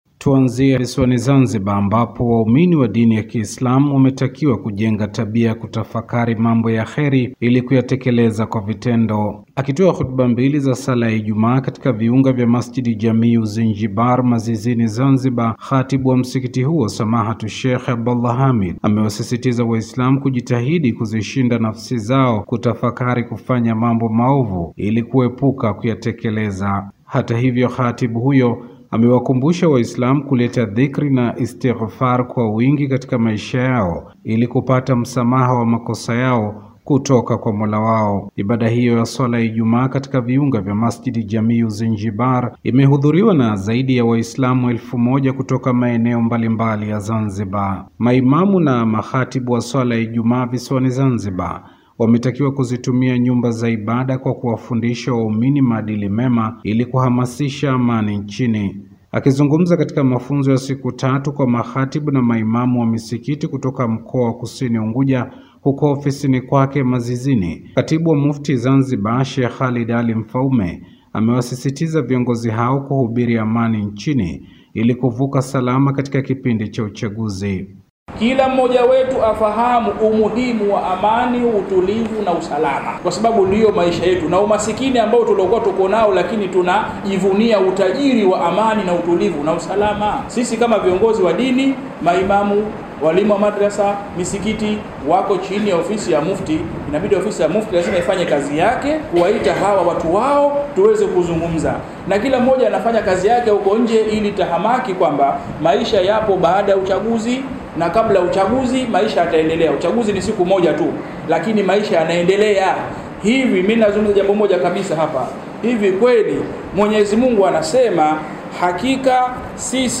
Habari / Afrika